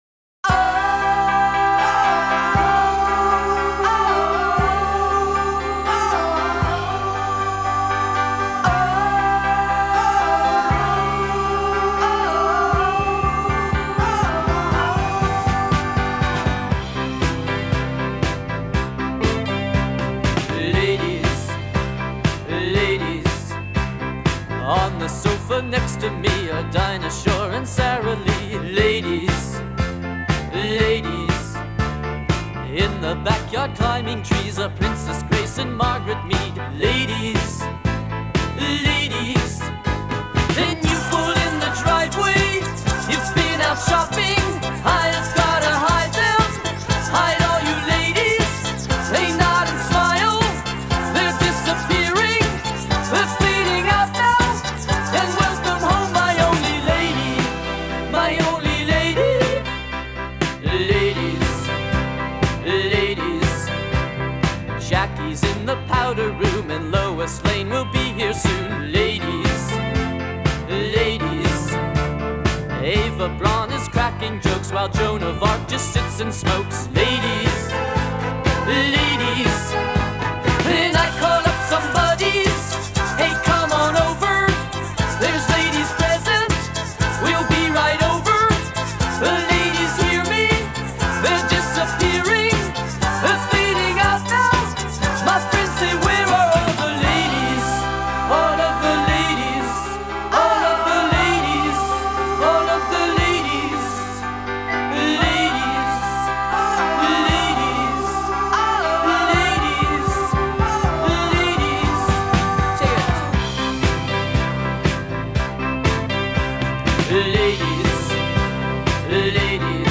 humoristische liedje
Amerikaans art-popband